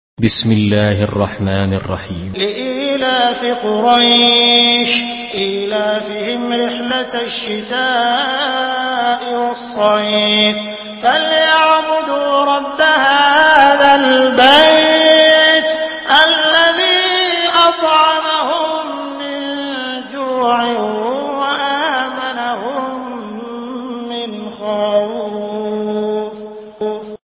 Surah Quraish MP3 by Abdul Rahman Al Sudais in Hafs An Asim narration.
Murattal Hafs An Asim